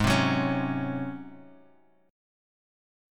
Ab11 Chord
Listen to Ab11 strummed